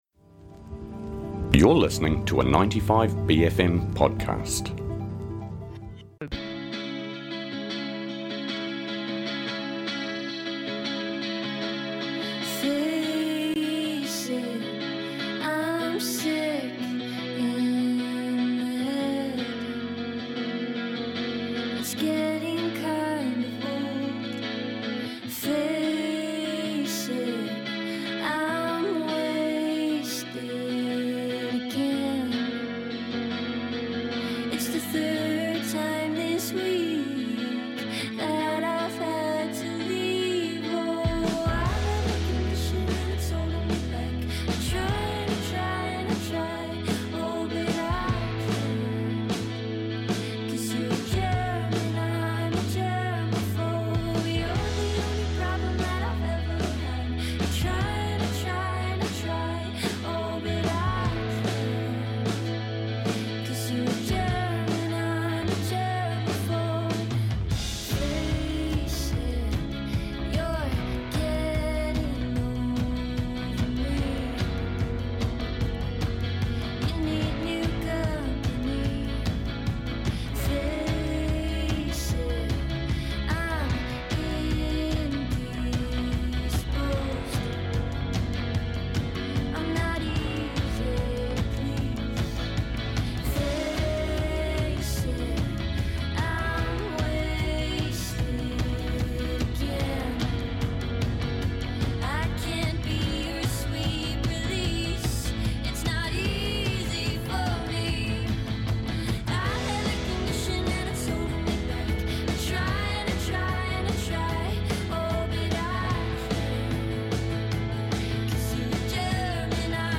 dials in from Ōtautahi to chat about her EP Emotional Affair, released today.